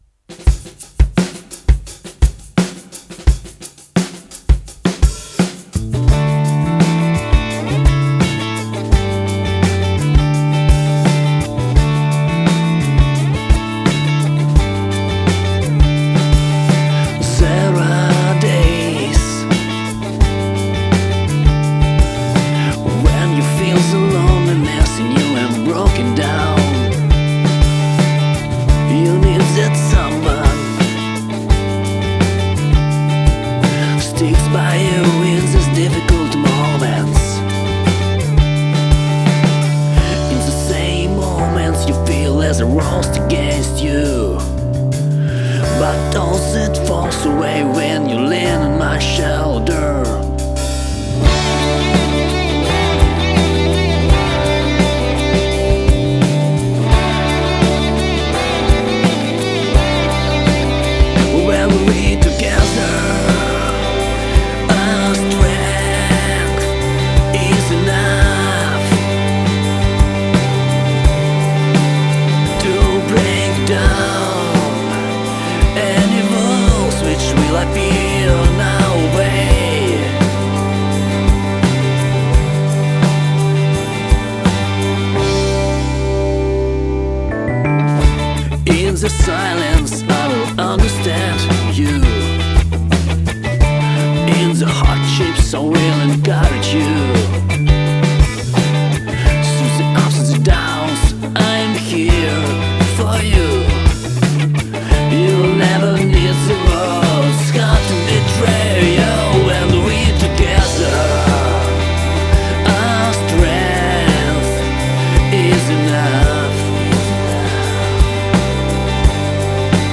• Genre: Alternative